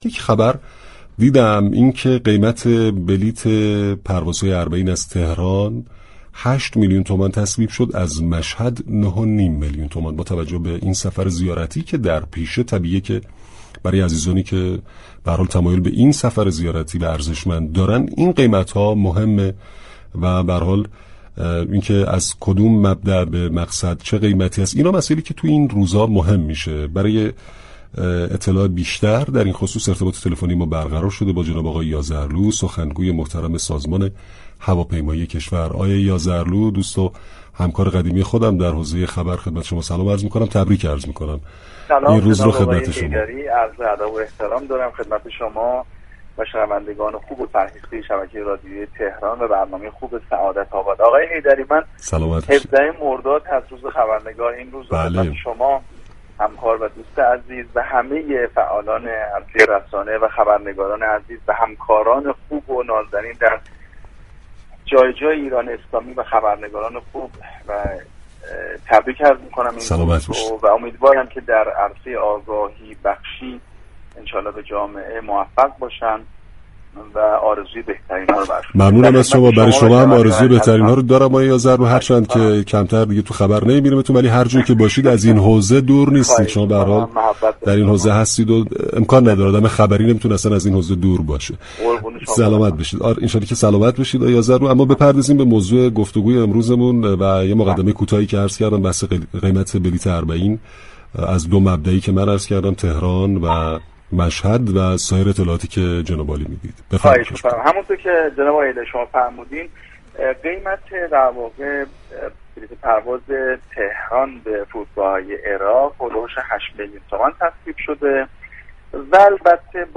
در گفتگو با برنامه سعادت آباد رادیو تهران